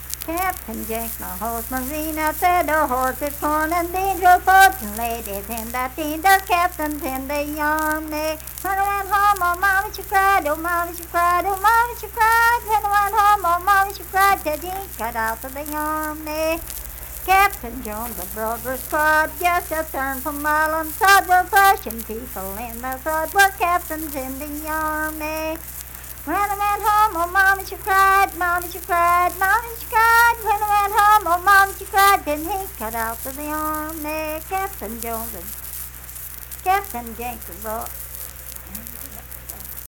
Unaccompanied vocal music performance
Dance, Game, and Party Songs
Voice (sung)
Logan County (W. Va.)